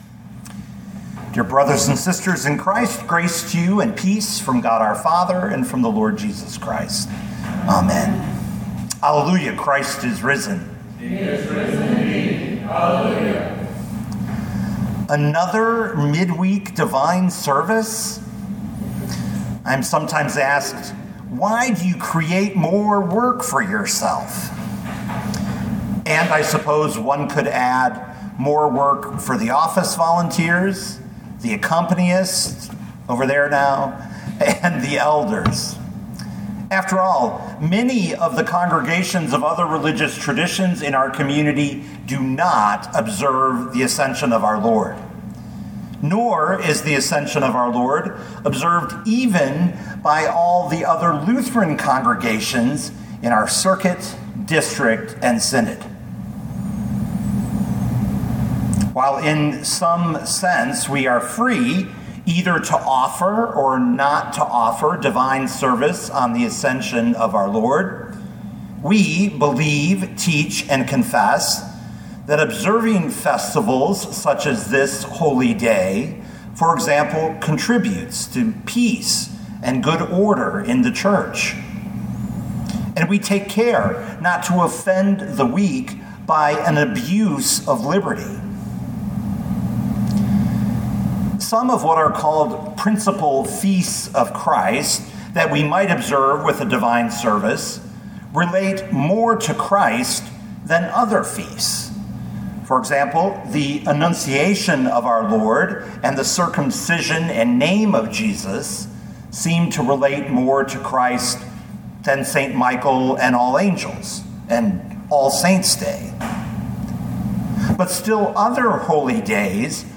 2025 Luke 24:50-53 Listen to the sermon with the player below, or, download the audio.